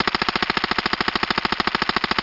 uzi.wav